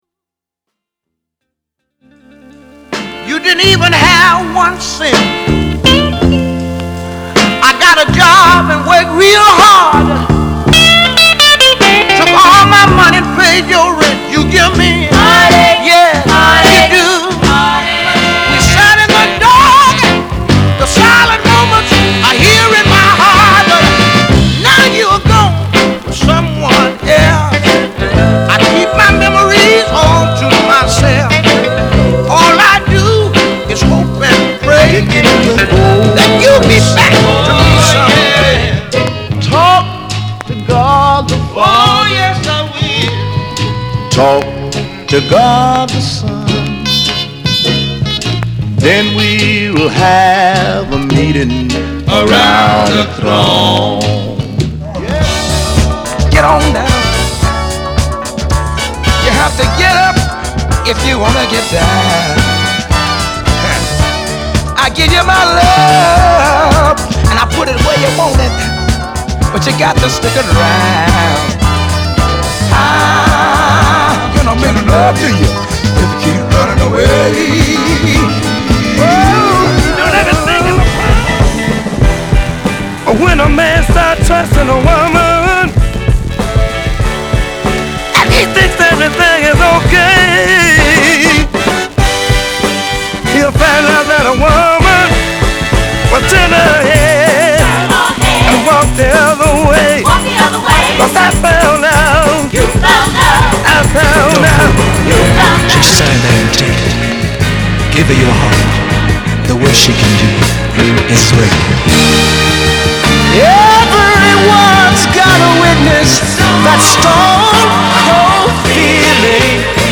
/盤質/両面やや傷あり/US PRESS